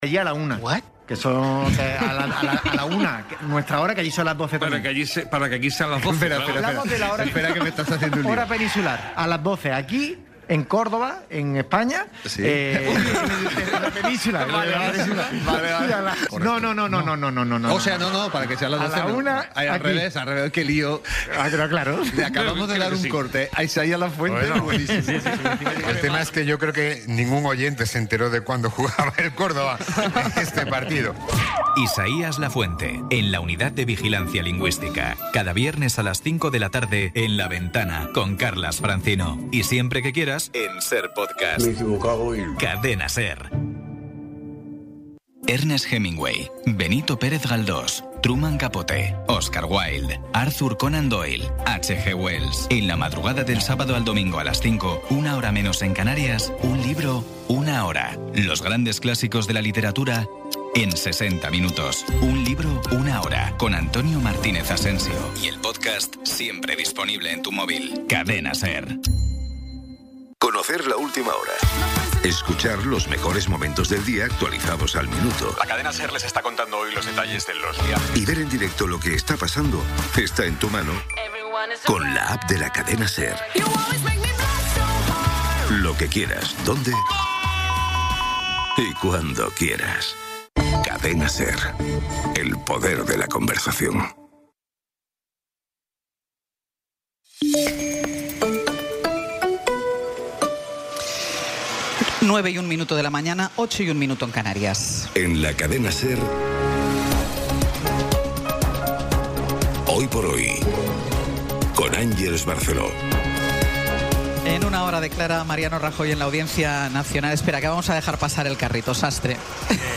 Resumen informativo con las noticias más destacadas del 23 de abril de 2026 a las nueve de la mañana.